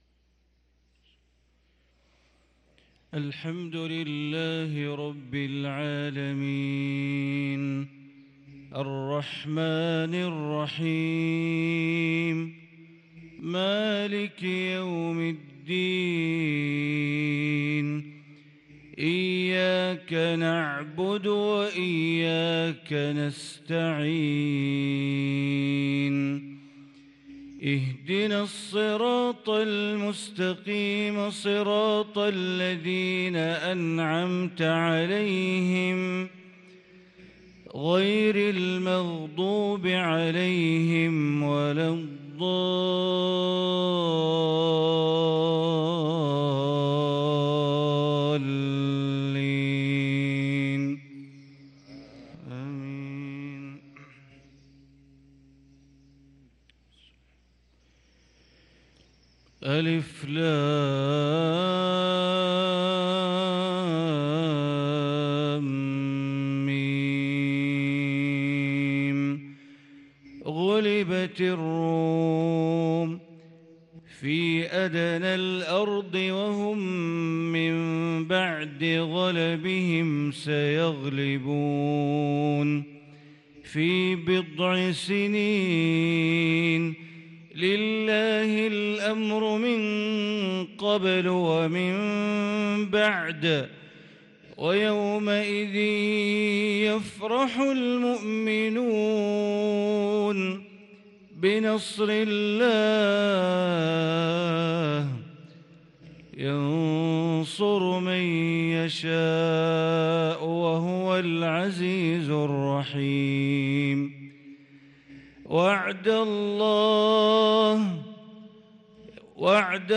صلاة الفجر للقارئ بندر بليلة 8 ربيع الآخر 1444 هـ
تِلَاوَات الْحَرَمَيْن .